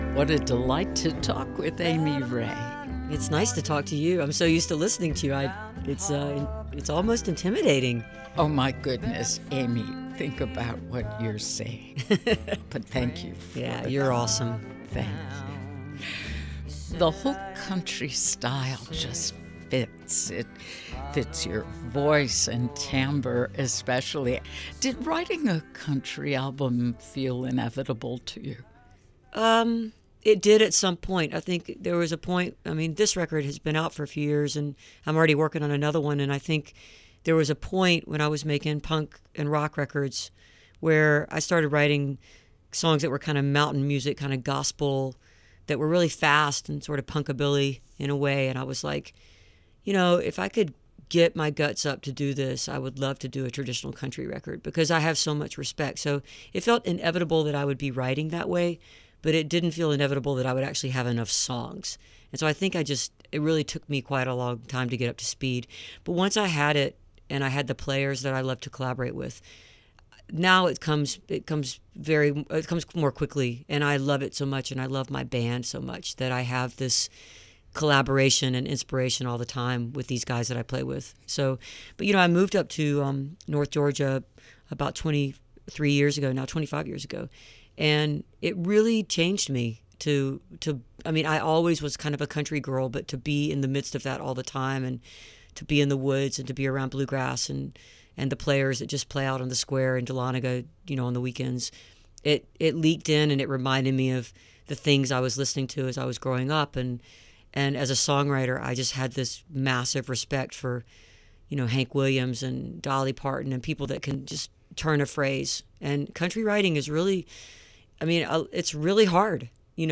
(recorded from webcast)
03. interview (6:52)